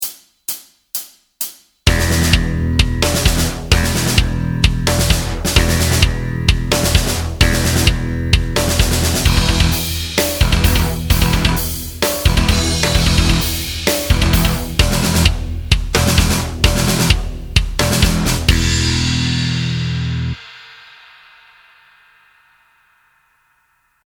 To enter the Guitar Mode Contest, download the backing track below and add your own guitar tracks.